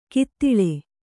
♪ kittiḷe